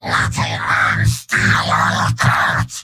Giant Robot lines from MvM. This is an audio clip from the game Team Fortress 2 .